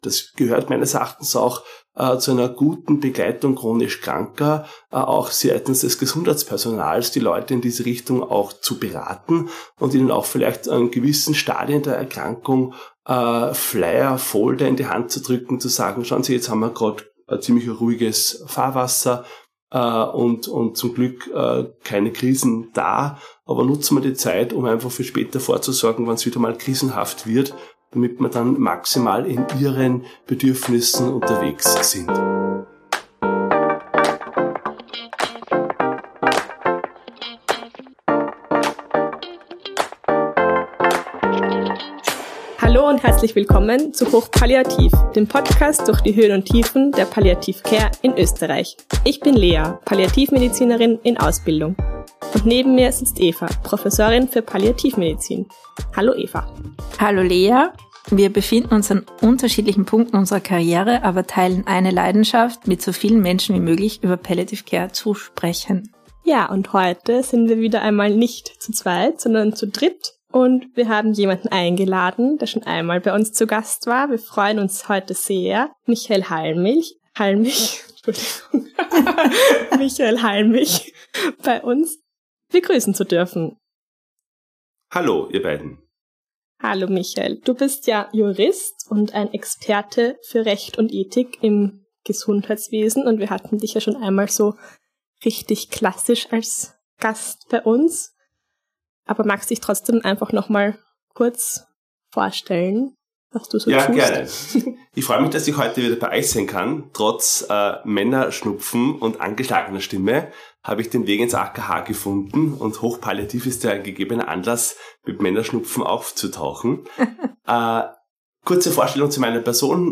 Zum Auftakt nach einer kurzen Pause gibt es heute wieder ein wunderbares Gespräch mit einem Gast, den wir immer wieder gerne zu uns einladen.